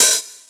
Closed Hats
edm-hihat-30.wav